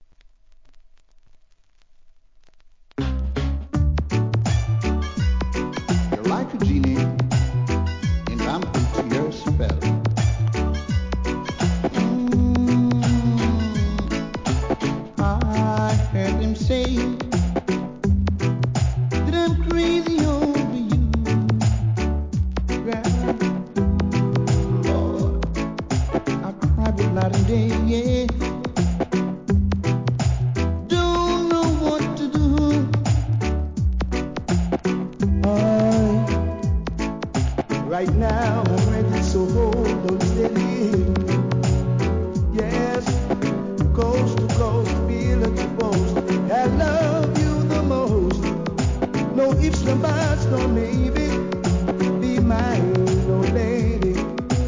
REGGAE
1995年、UK, JAMAICAラバーズ・コレクション♪